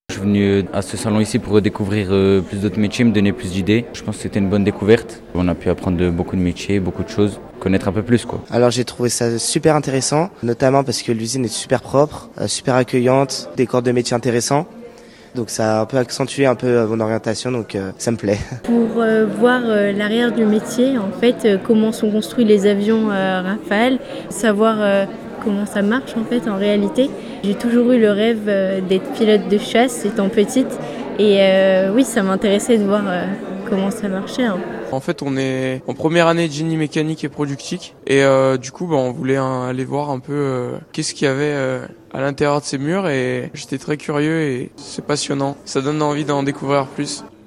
Le site d'Argonay de Dassault Aviation a organisé ce mercredi une journée portes ouvertes pour faire découvrir l'envers du décors au grand public, dans l'optique d'attirer des potentiels futurs salariés.
Parmi ces visiteurs d'un jour, il y avait beaucoup d'étudiants ou futurs étudiants, attirés par cette occasion unique.
MT Jeunes étudiants 1 - Portes ouvertes Dassault (47'')